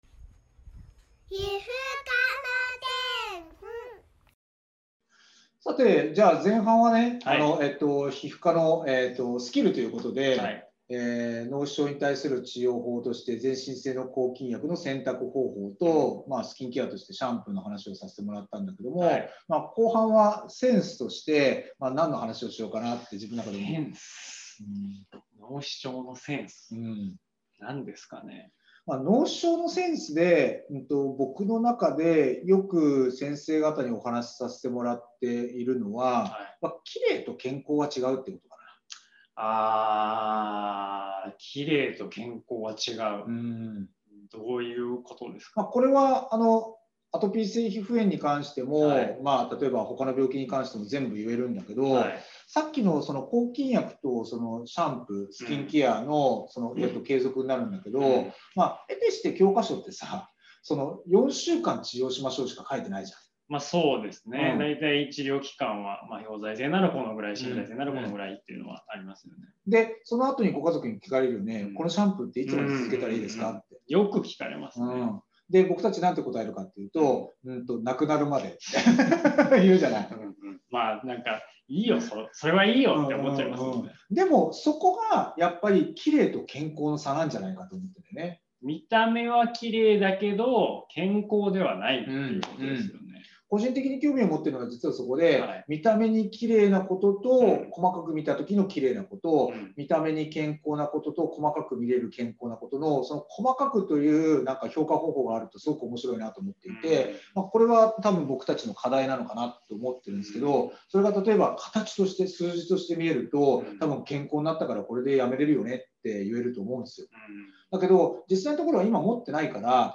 対談